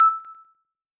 menuclick.mp3